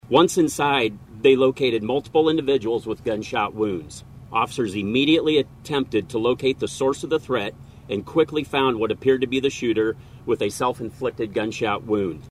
Mitch Mortvet, Assistant Director for the Iowa Criminal of Investigations, spoke at 3pm giving further updates.